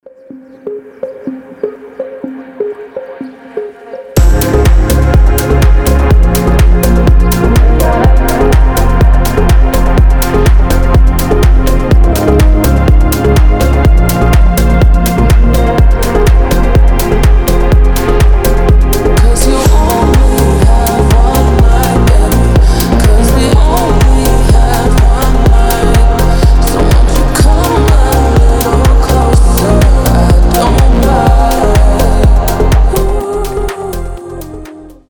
deep house
атмосферные
мелодичные
расслабляющие
Пение птиц